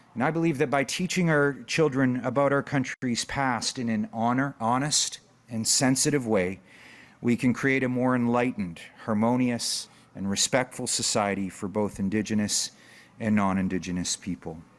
Greg Rickford, Ontario’s Minister of Indigenous Affairs helped make the announcement today.